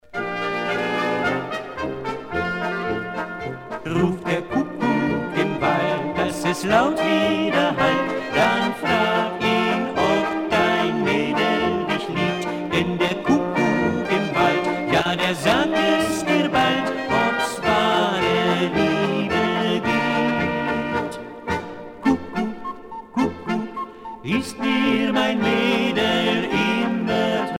danse : polka